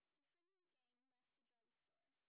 sp18_white_snr30.wav